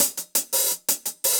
Index of /musicradar/ultimate-hihat-samples/170bpm
UHH_AcoustiHatB_170-03.wav